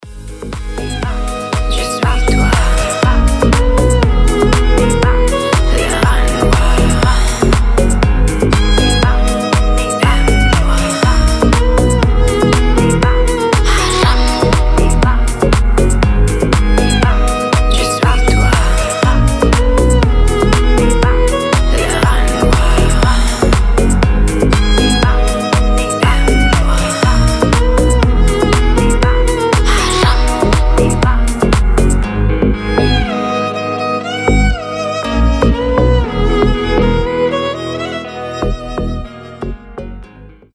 • Качество: 320, Stereo
красивые
deep house
скрипка
чувственные
восточные